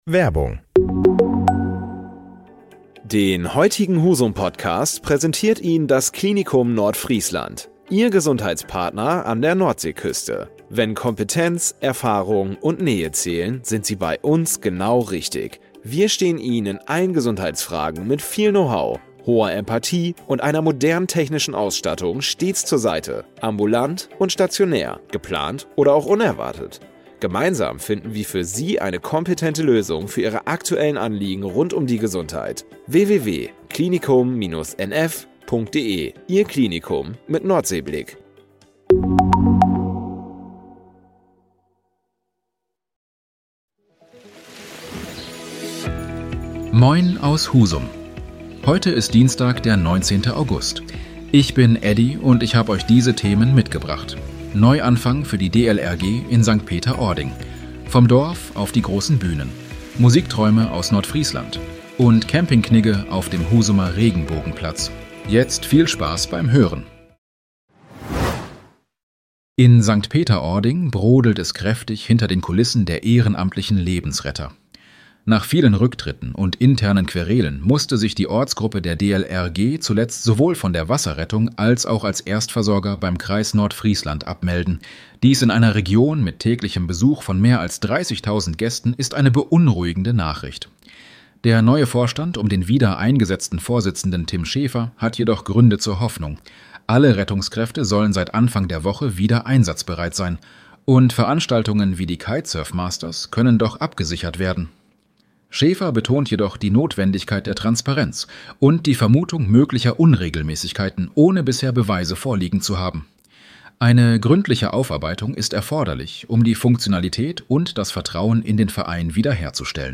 Deine täglichen Nachrichten